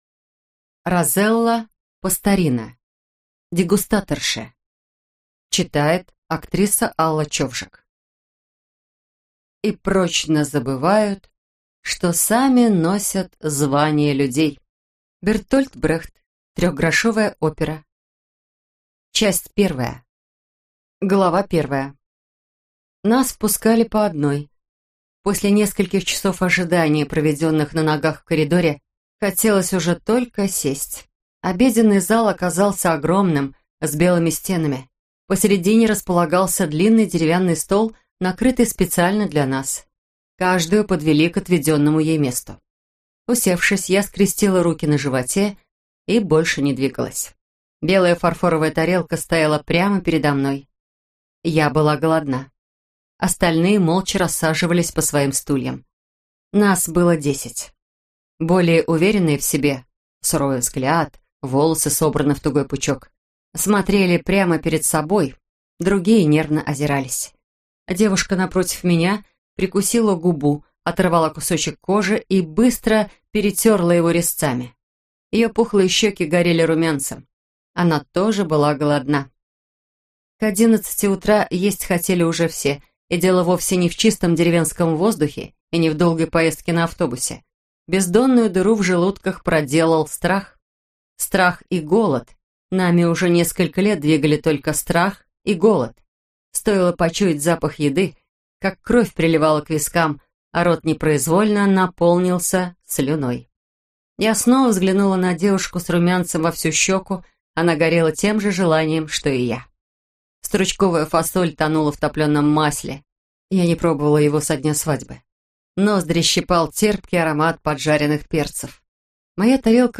Аудиокнига Дегустаторши | Библиотека аудиокниг